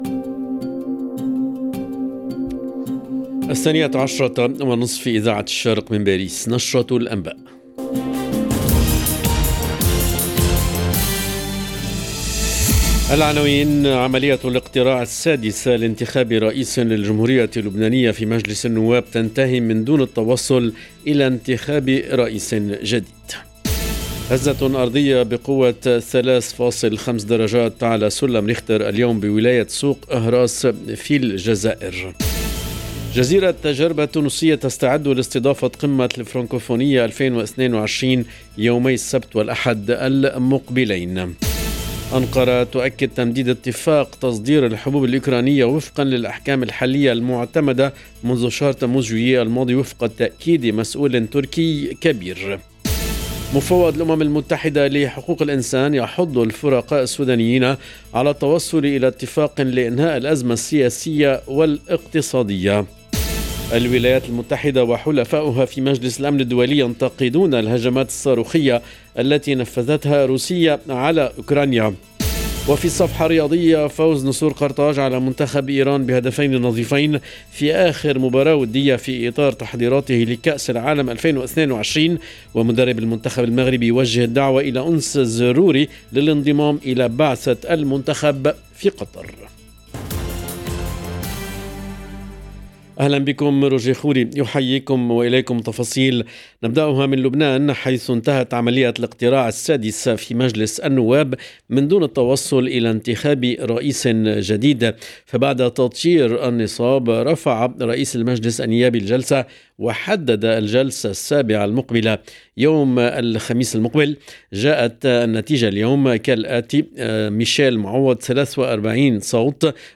LE JOURNAL EN LANGUE ARABE DE MIDI 30 DU 17/11/22